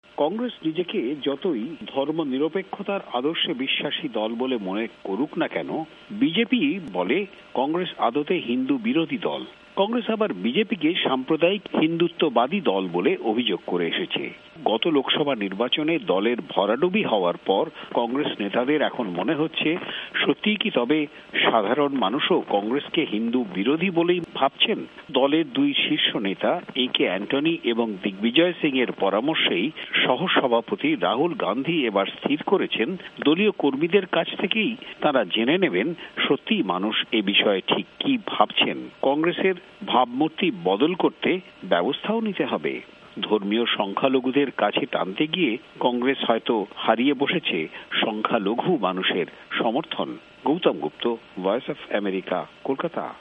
ভয়েস অব আমেরিকার কলকাতা সংবাদদাতাদের রিপোর্ট